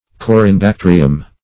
corynebacterium \co`ry*ne`bac*ter"i*um\, n. (MIcrobiol.)